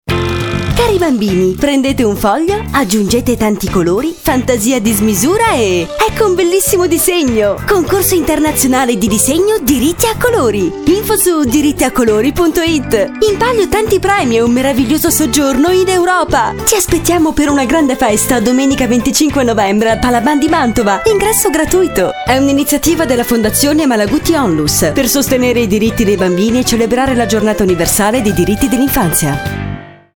SPOT RADIO
spot_radio.mp3